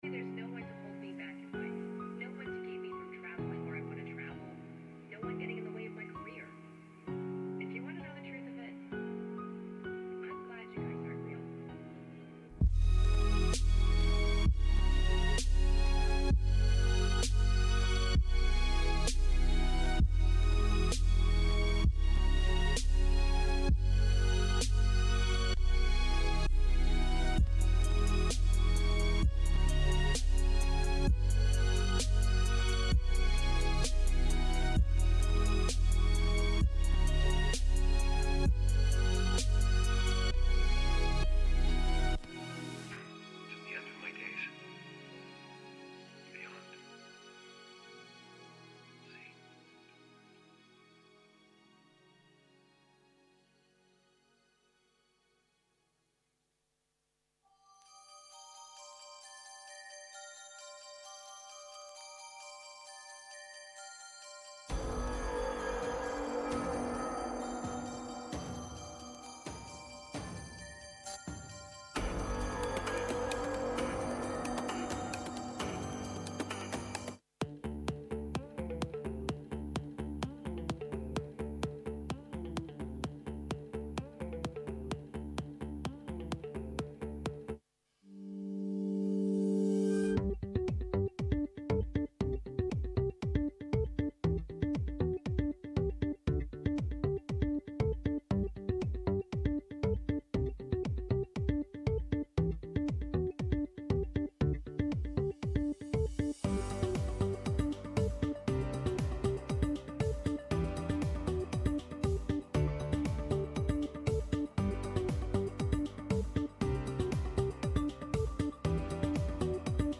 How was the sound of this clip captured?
Took place at: Amora Hotel Jamison Sydney & Online